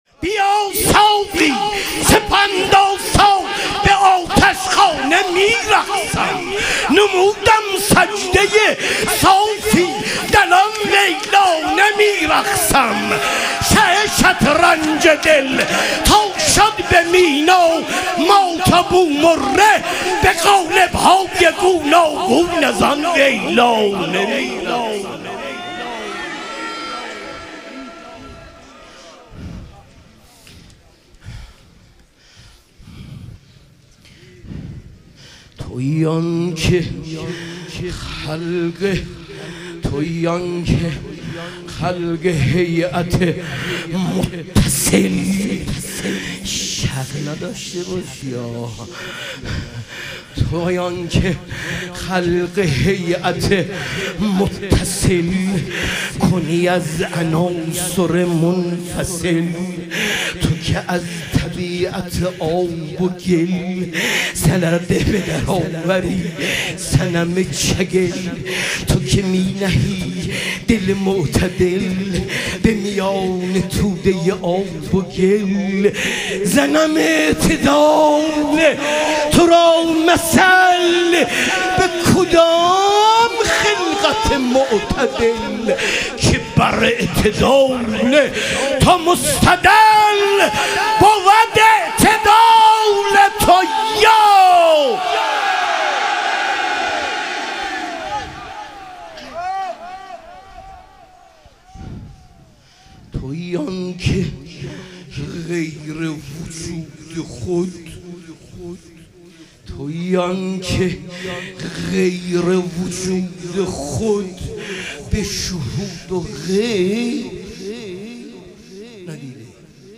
ظهور وجود مقدس حضرت امیرالمومنین علیه السلام - مدح و رجز